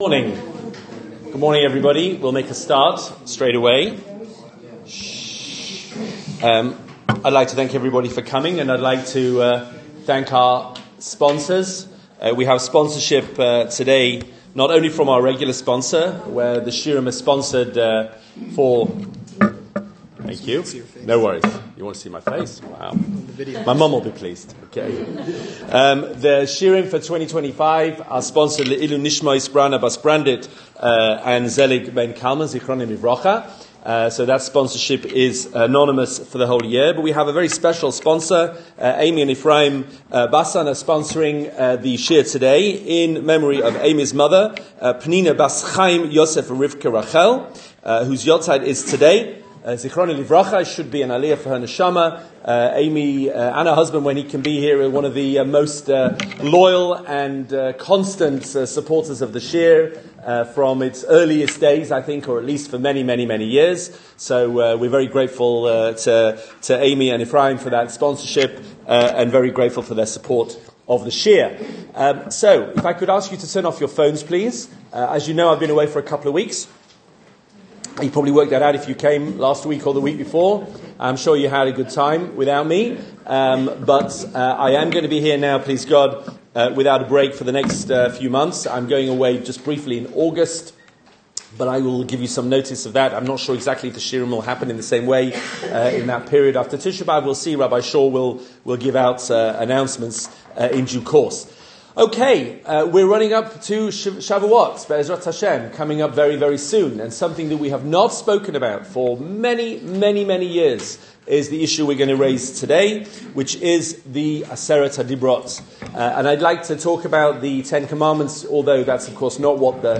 Two audio shiurim